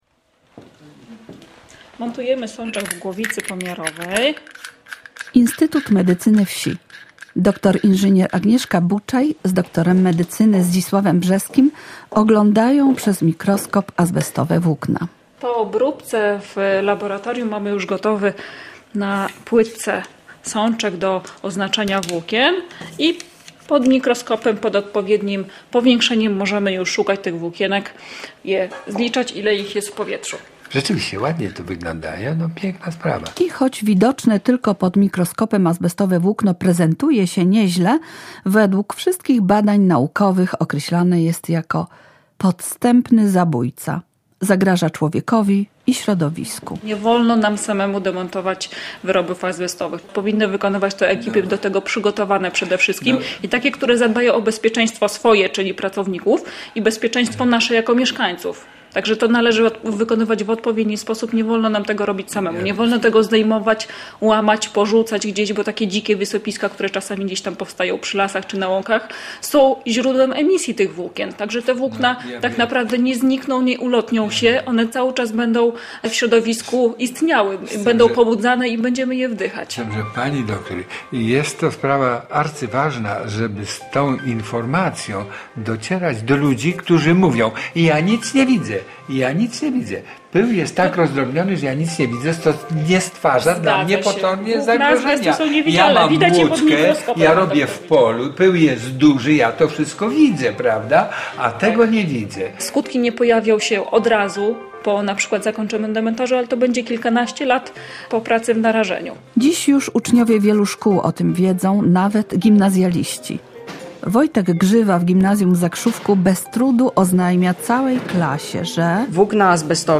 Reportaż pokazuje tempo realizowanego przez Urząd Marszałkowski Województwa Lubelskiego projektu, którego celem było pozbycie się azbestu.